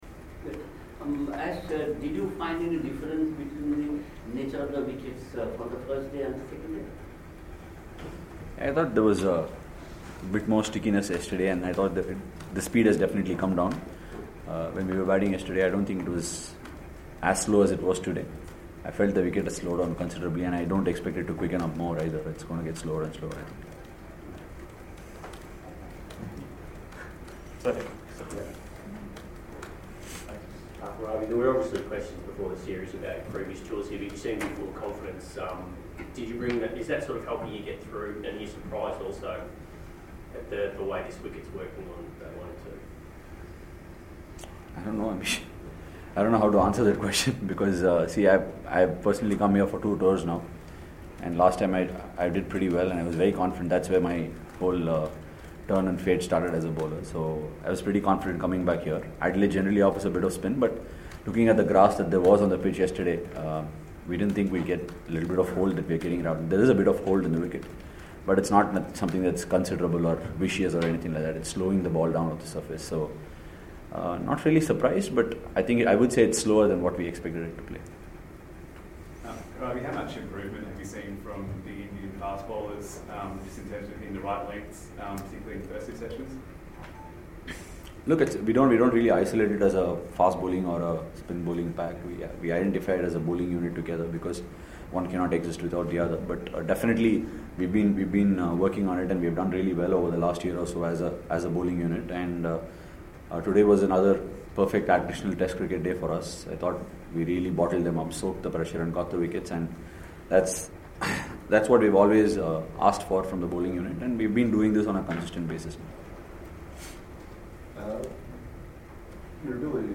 R Ashwin interacted with the media on Friday, December 7 at the Adelaide Oval after Day 2 of the 1st Test against Australia.